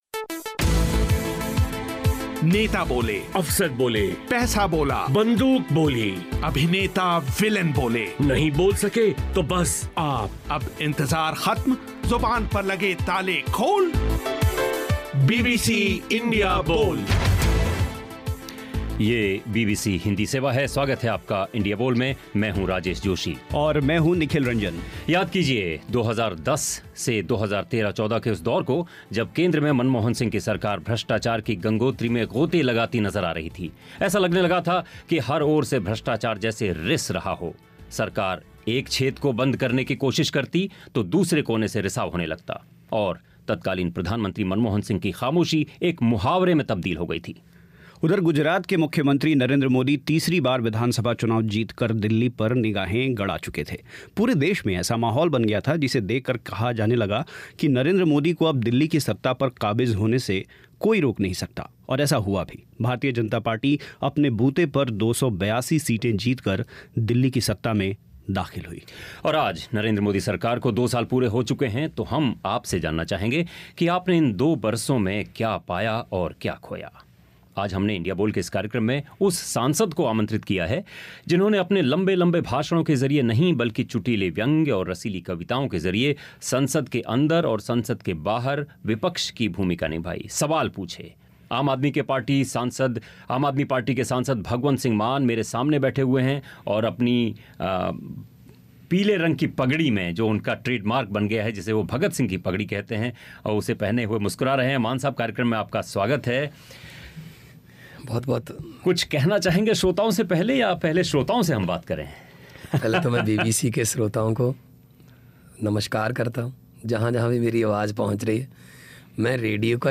सुनिए लोगों की राय